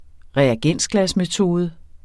Udtale [ ʁεaˈgεnˀsglas- ]